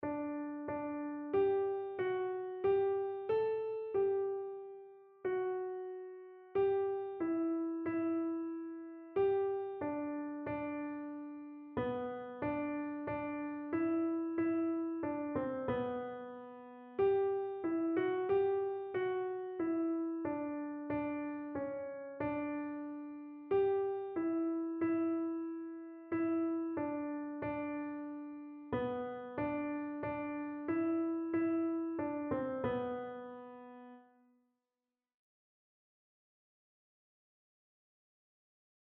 Einzelstimmen (Unisono)